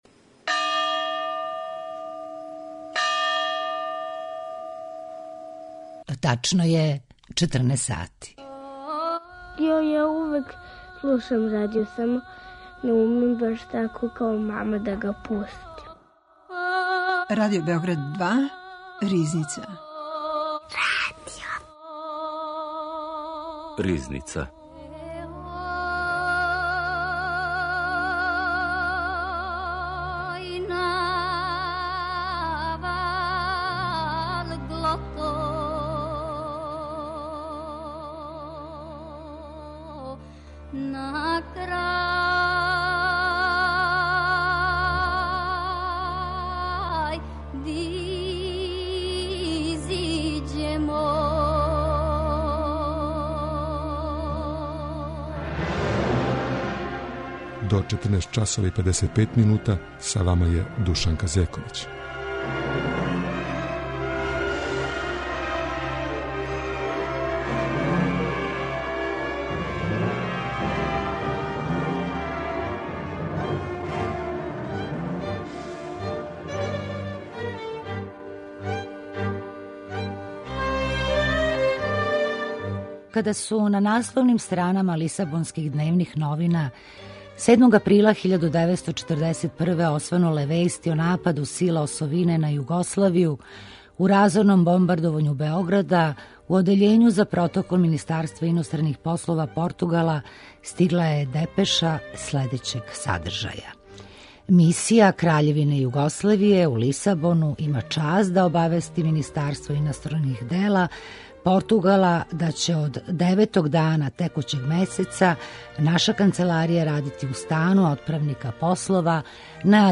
Гост Ризнице је амбасадор и писац др Душко Лопандић